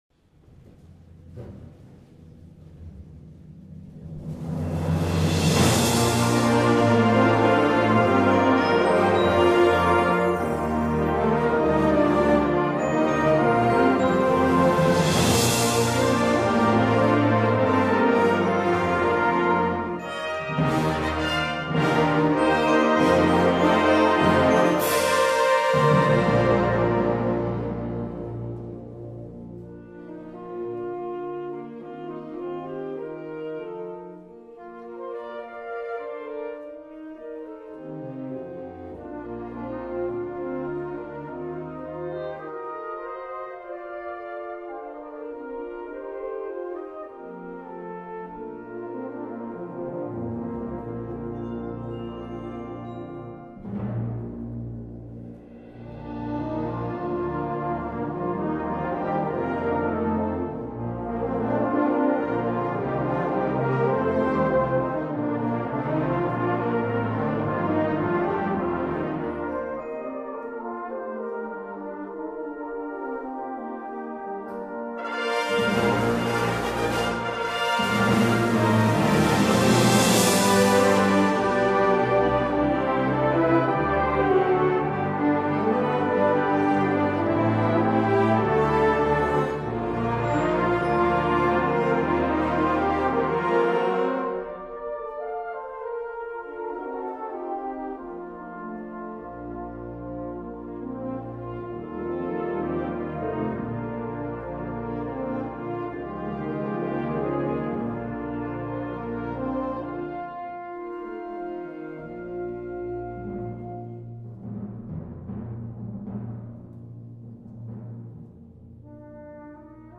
Timpani
Mallets